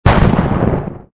lightning_2.ogg